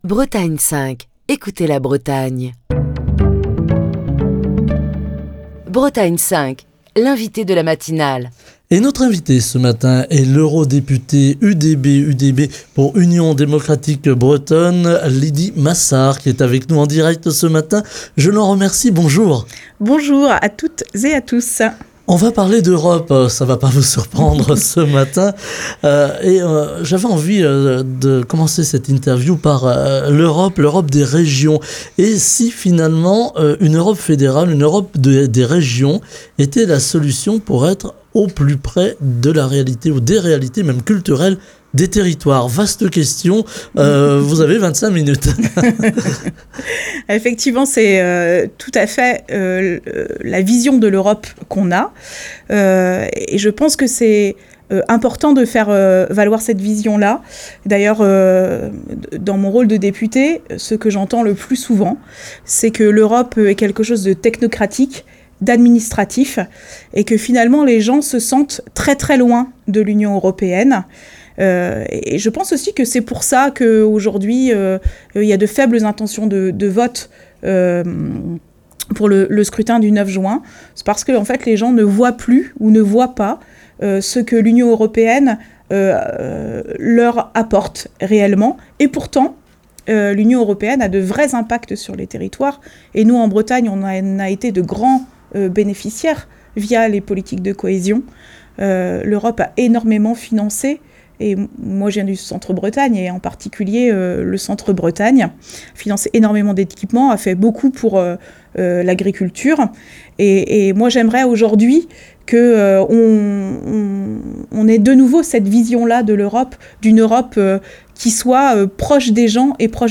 Ce matin, nous parlons de l'Europe des régions. Et si une Europe fédérale était la solution pour être au plus près des réalités des territoires ? Nous posons la question à Lydie Massard, eurodéputée UDB (Union Démocratique Bretonne) qui est l'invitée de Bretagne 5 Matin.